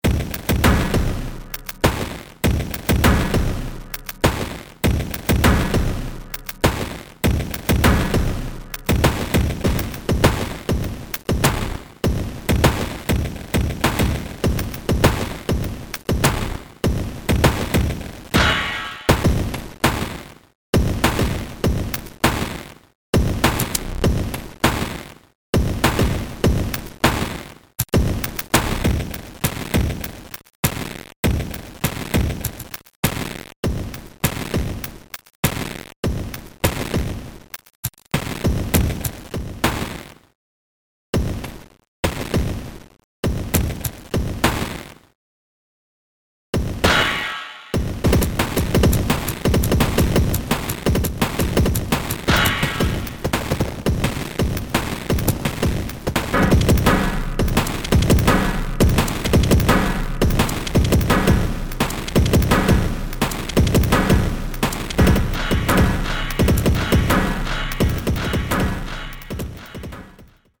Synthetic drums heavily processed through granular effects.